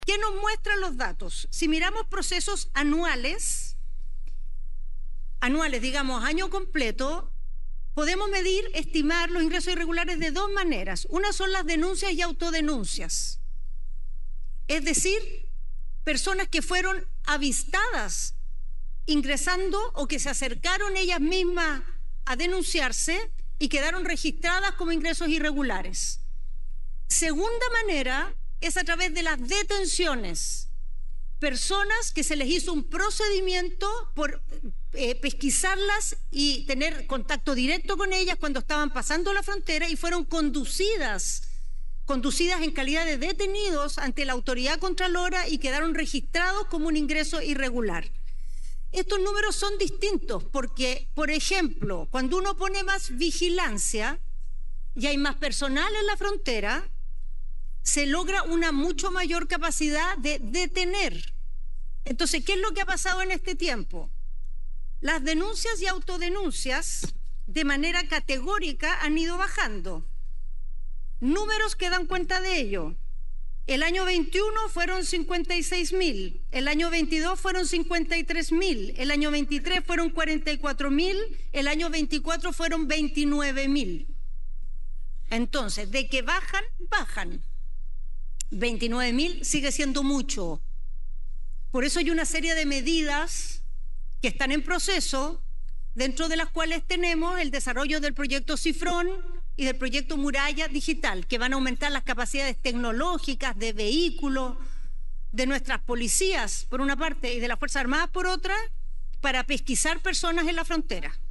Discusión en Sala
Por su parte, la ministra del Interior, Carolina Tohá, dijo que la medición de ingresos irregulares en la frontera es estimativa, a diferencia de los datos objetivos sobre detenciones y delitos.